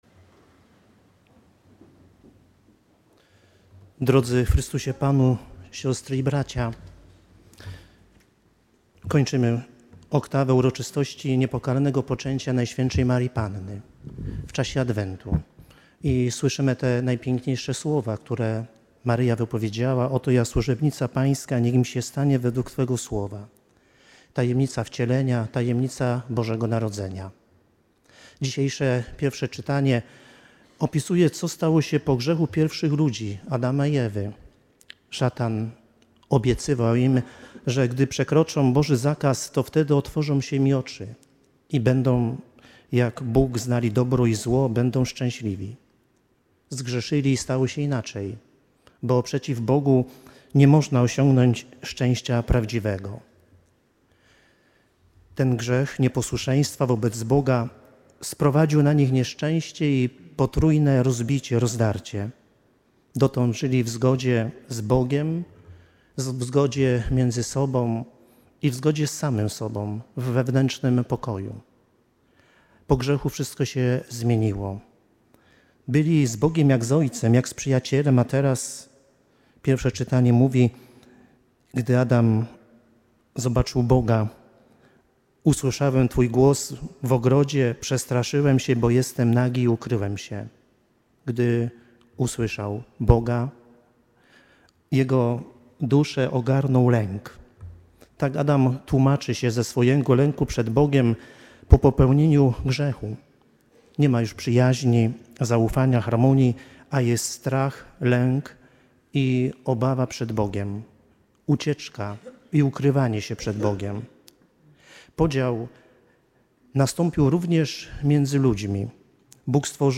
Podczas liturgii kapłan wygłosił kazanie, w którym odwoływał się do Liturgii Słowa przeznaczonej na dzień Uroczystości Niepokalanego Poczęcia NMP. Komentując fragment Księgi Rodzaju o grzechu nieposłuszeństwa pierwszych rodziców, przypomniał, że nie można osiągnąć prawdziwego szczęścia występując przeciw Bogu.